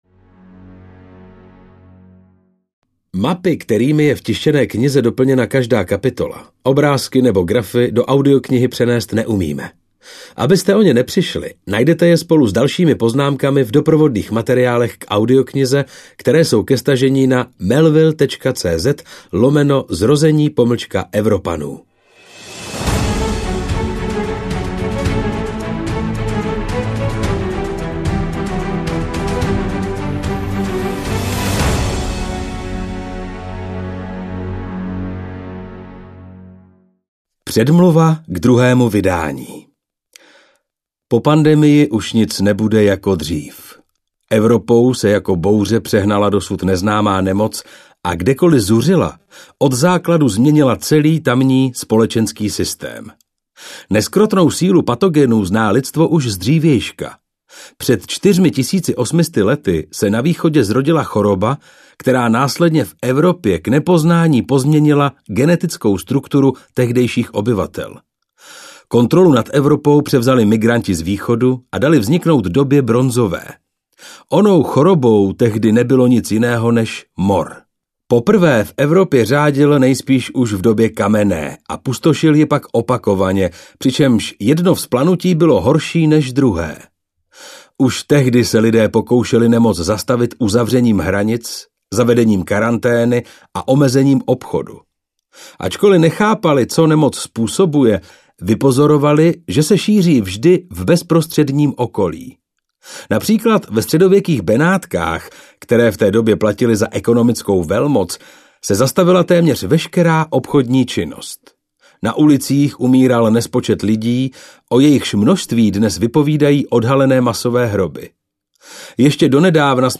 Zrození Evropanů audiokniha
Ukázka z knihy
zrozeni-evropanu-audiokniha